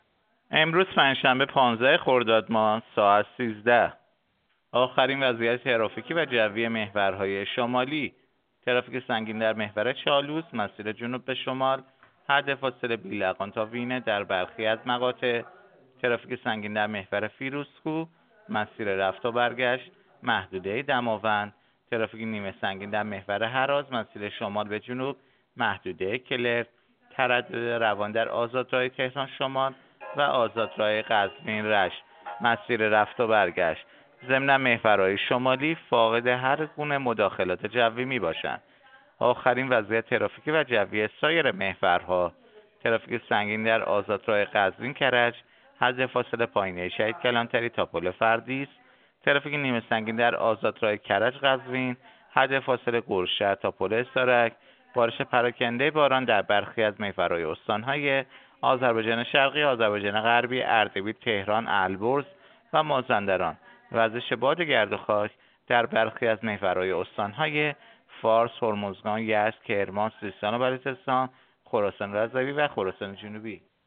گزارش رادیو اینترنتی از آخرین وضعیت ترافیکی جاده‌ها ساعت 13 چهاردهم خرداد؛